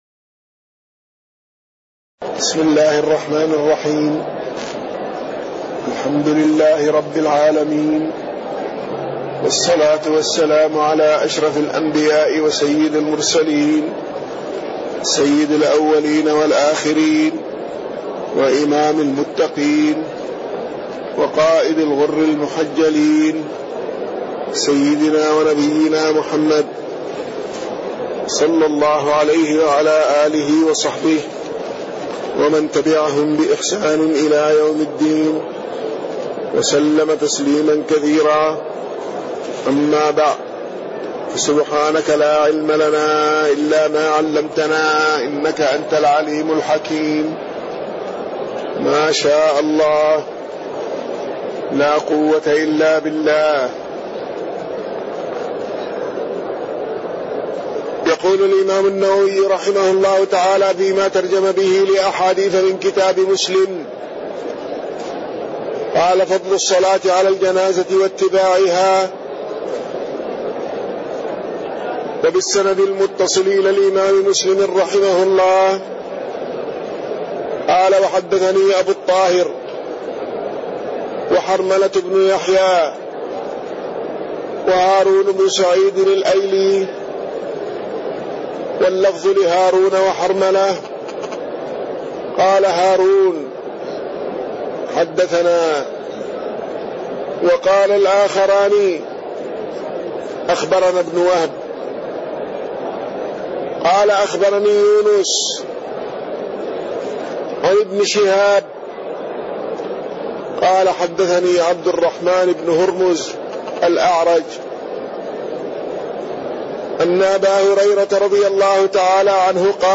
تاريخ النشر ٢٥ ربيع الأول ١٤٣٢ هـ المكان: المسجد النبوي الشيخ